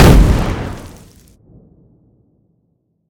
small-explosion-5.ogg